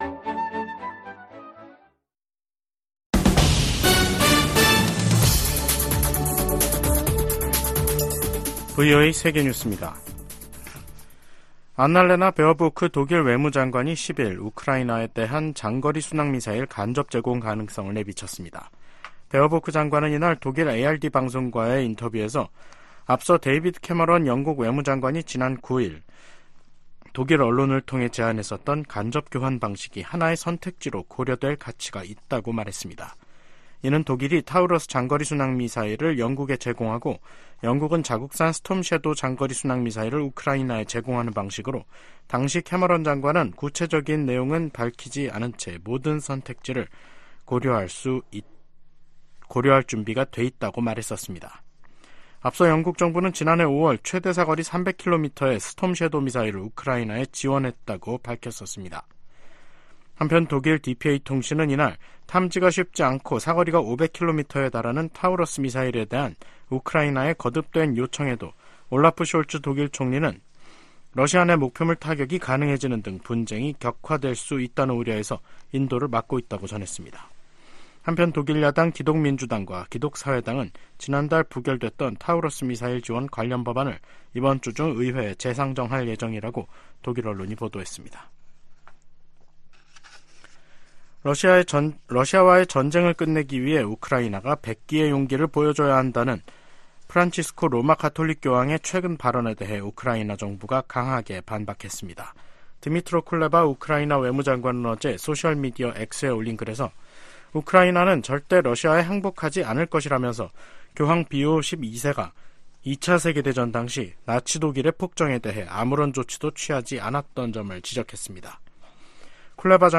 VOA 한국어 간판 뉴스 프로그램 '뉴스 투데이', 2024년 3월 11일 3부 방송입니다. 미국은 전제 조건 없이 대화에 열려 있지만 북한은 관심 징후를 전혀 보이지 않고 있다고 미 국무부가 지적했습니다. 미국과 한국의 북 핵 대표들의 직책 또는 직급이 변화를 맞고 있습니다. 한반도 비핵화 과정의 중간 조치를 고려할 수 있다는 미 당국자들의 언급에 전문가들은 북한 핵 역량 검증의 어려움을 지적했습니다.